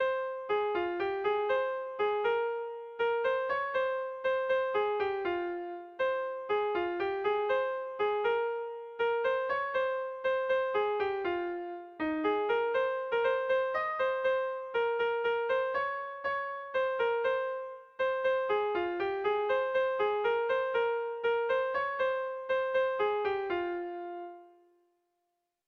Irrizkoa
ABDAB